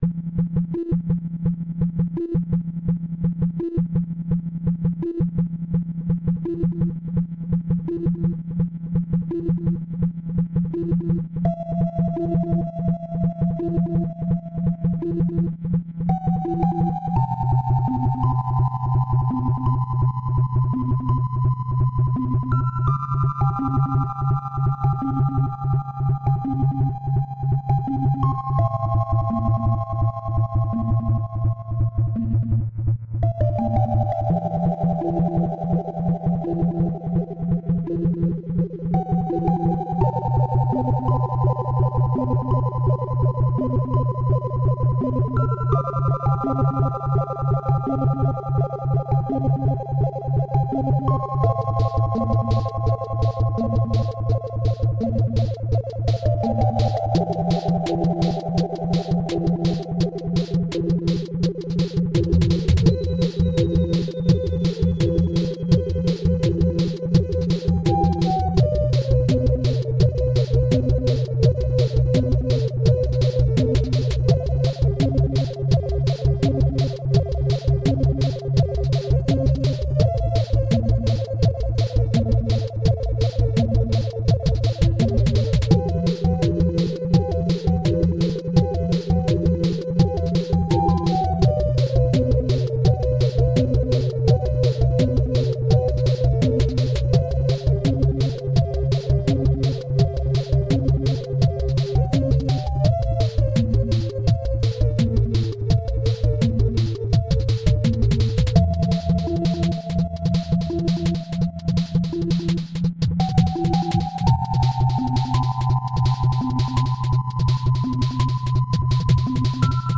ASA - Analog Desktop Clock 1.5 kg.ogg